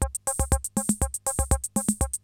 CR-68 LOOPS4 2.wav